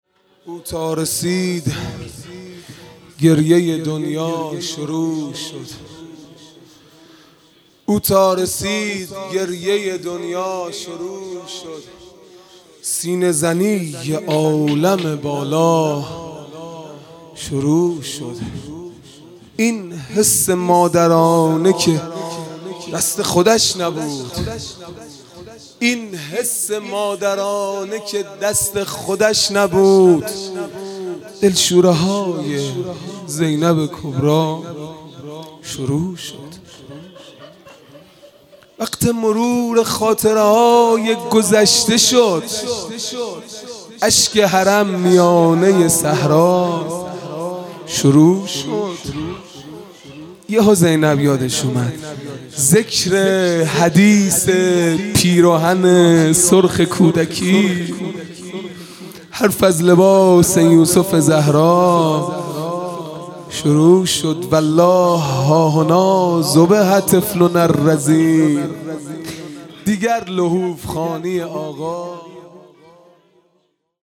خیمه گاه - هیئت بچه های فاطمه (س) - مرثیه | او تا رسید گریه دنیا شروع شد